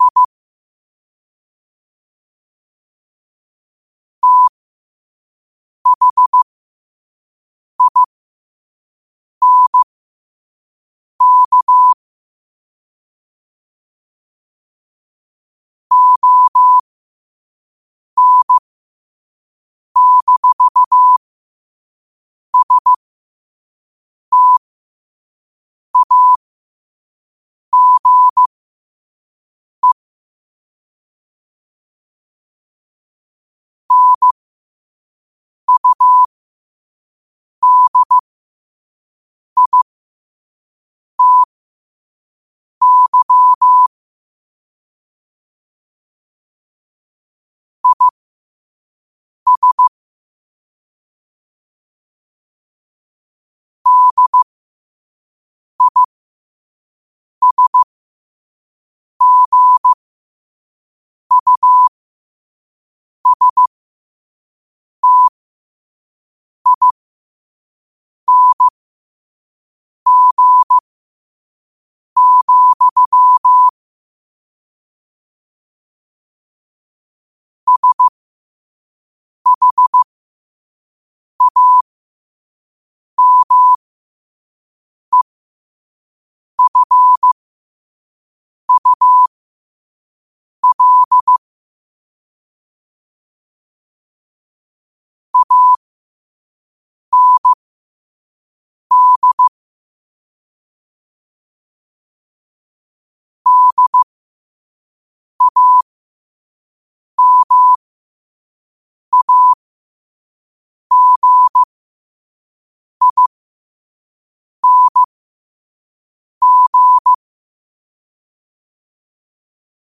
New quotes every day in morse code at 5 Words per minute.